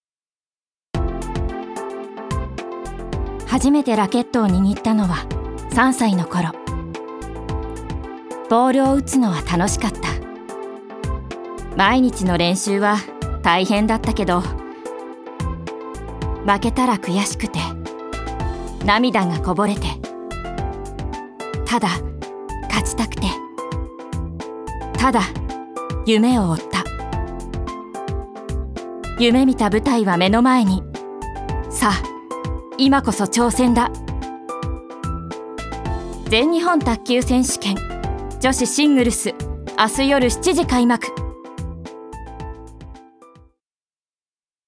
女性タレント
ナレーション４